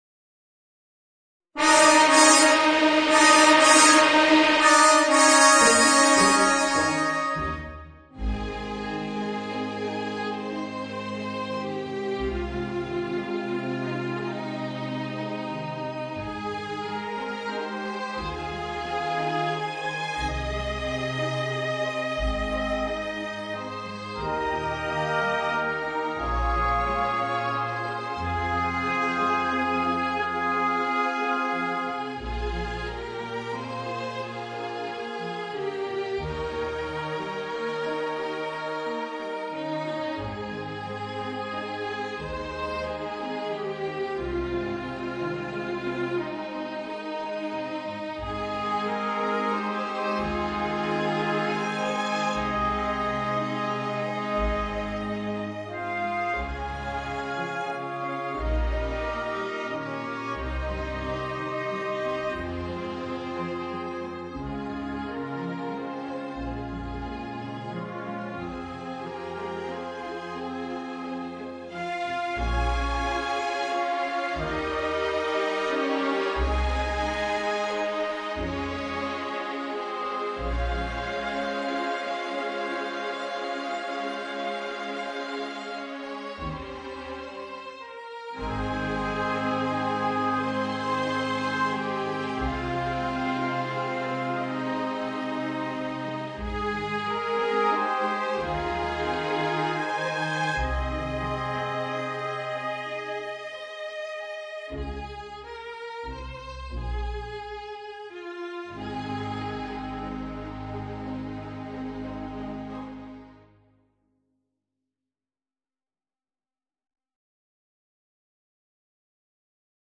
Voicing: Oboe and Orchestra